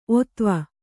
♪ otva